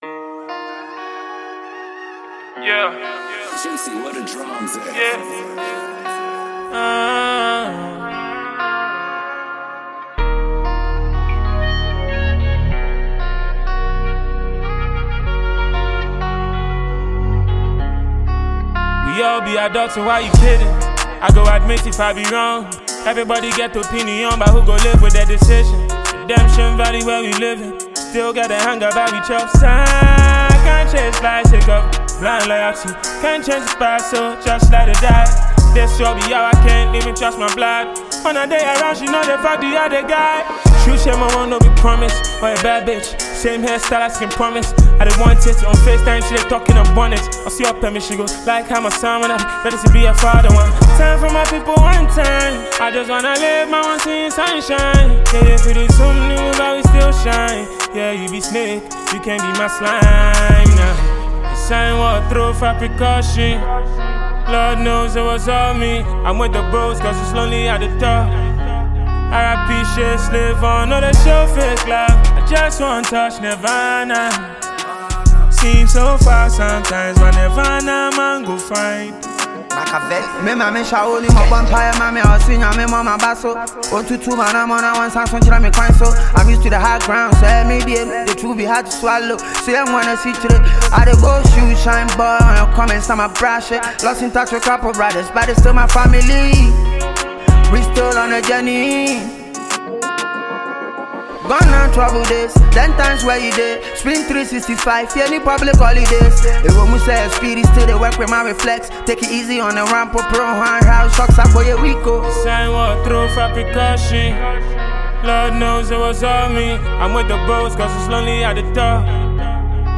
Ghanaian prolific rapper